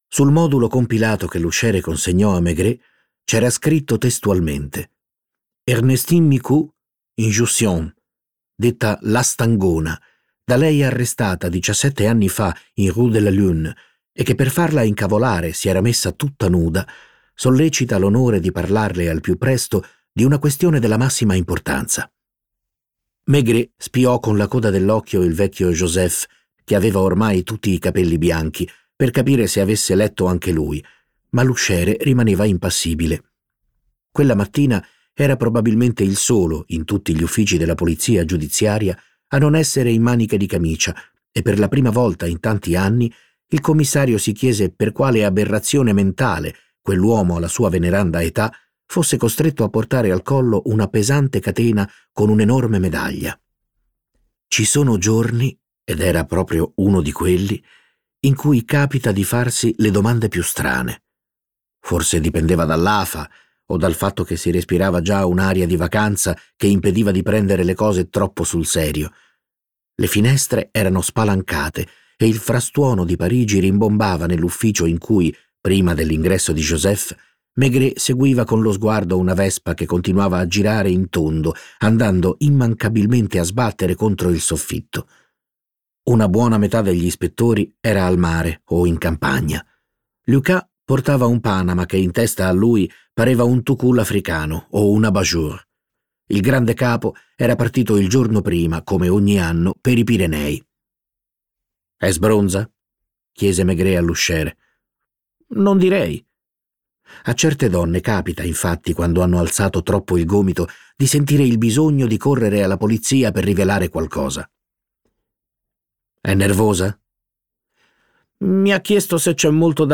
letto da Stefano Fresi
Versione audiolibro integrale